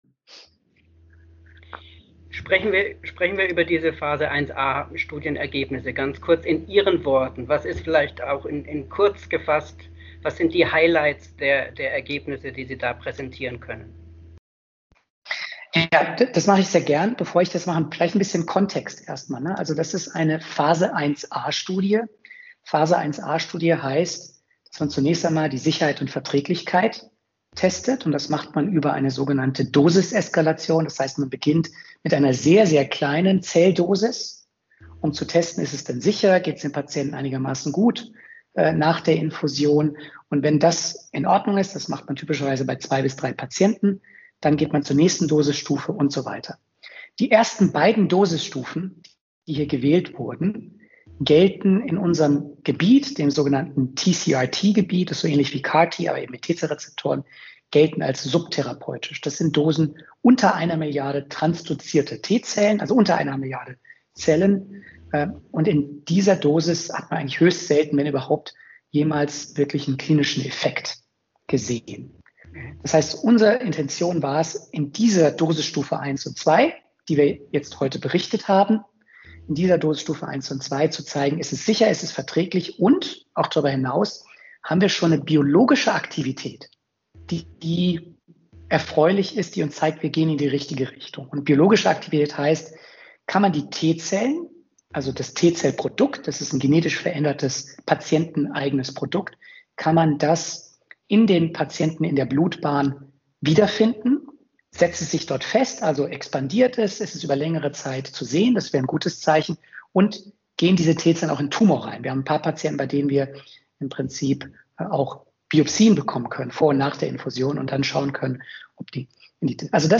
Ein adhoc-Podcast mit der Plattform LifeSciences.